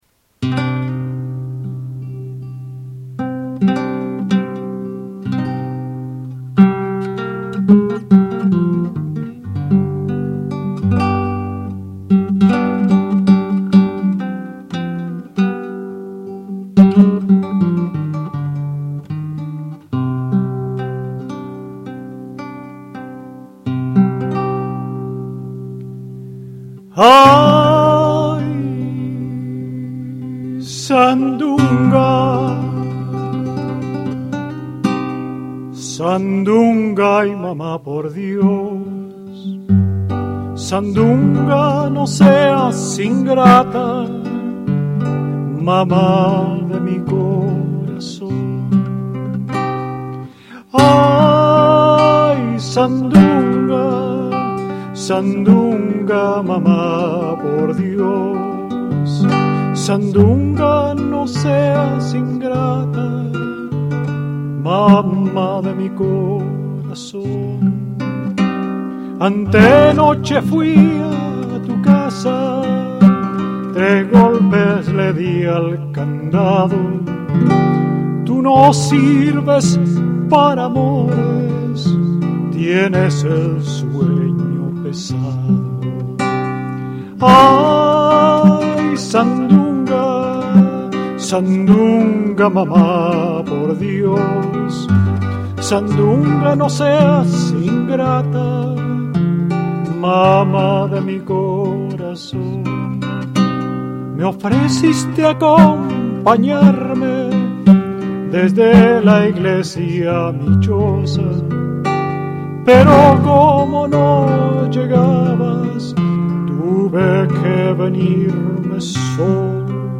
Mexico in the Heart on the Presentation of "No Word for Welcome" in Boston